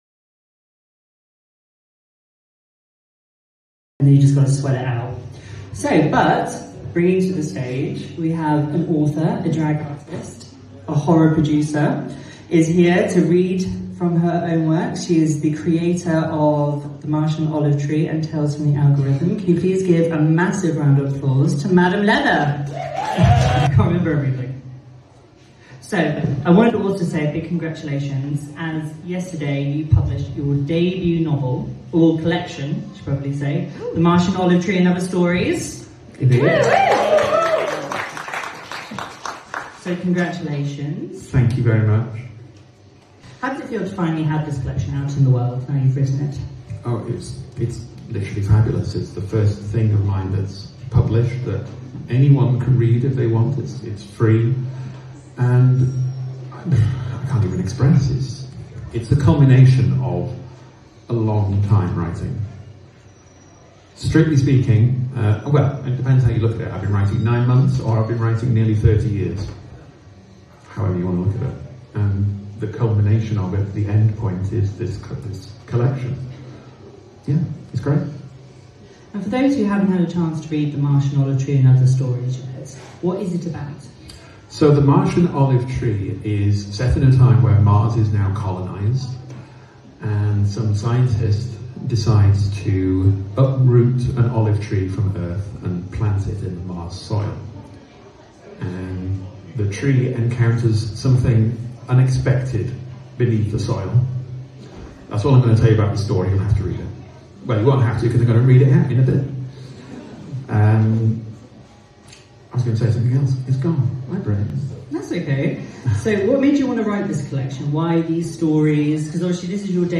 Bonnie in the Machine was later adapted into an official episodic audio series, expanding the story beyond the page and into sound.
Produced by ChapterCast, the series was designed to remain intimate and direct, prioritising atmosphere, pacing, and clarity over embellishment. Rather than adding external voices or sound-heavy reinterpretations, the adaptation focused on letting the language and perspective of the book carry the experience.